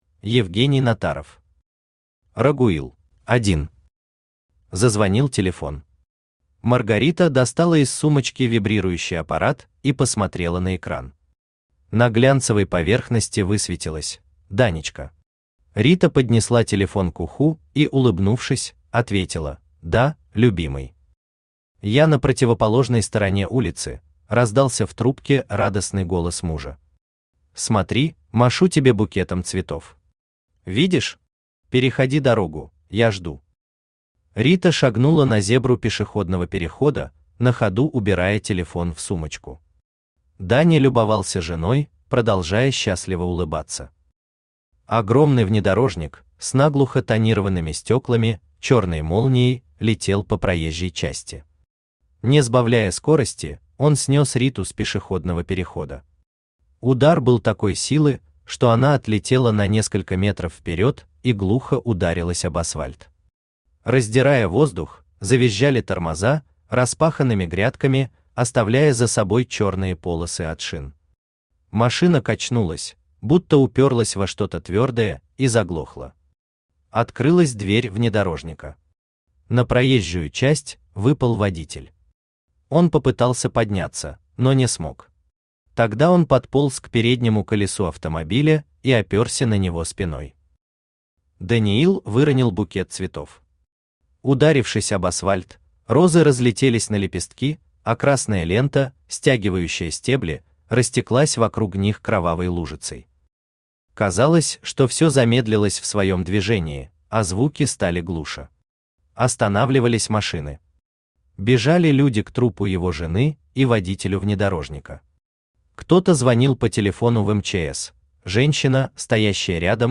Aудиокнига Рагуил Автор Евгений Натаров Читает аудиокнигу Авточтец ЛитРес.